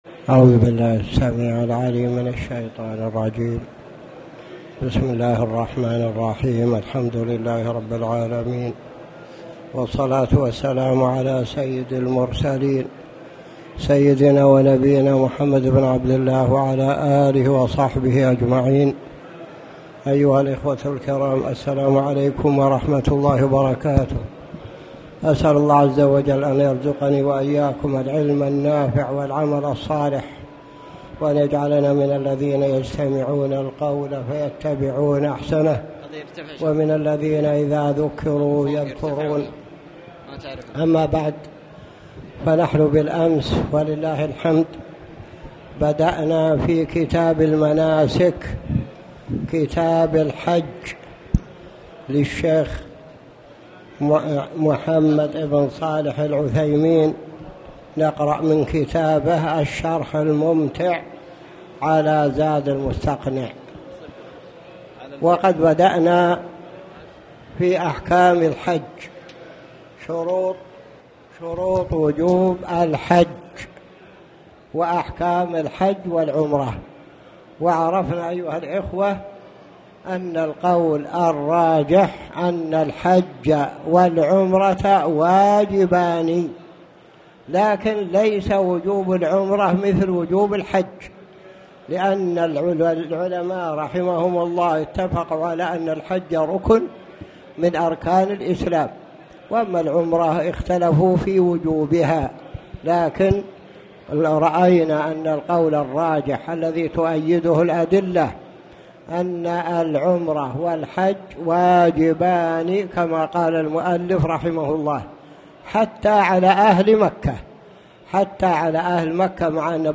تاريخ النشر ٢٩ شوال ١٤٣٨ هـ المكان: المسجد الحرام الشيخ